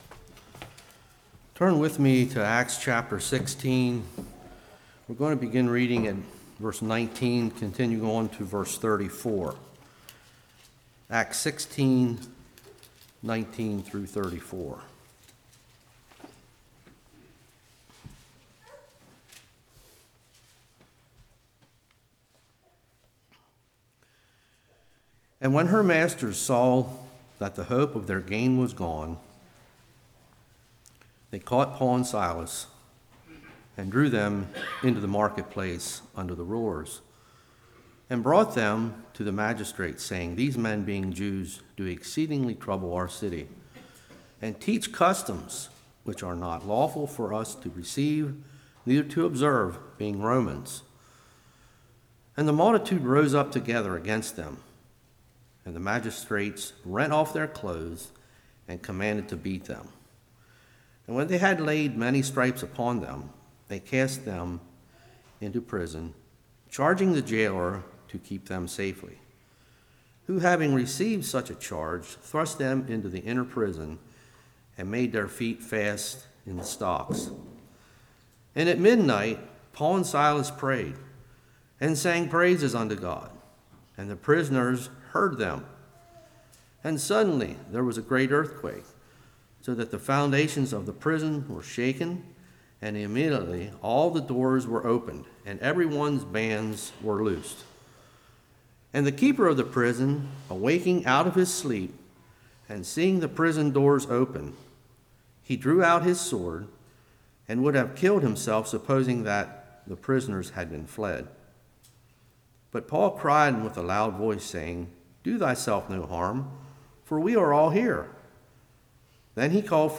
Acts 16:19-34 Service Type: Evening What is gentleness?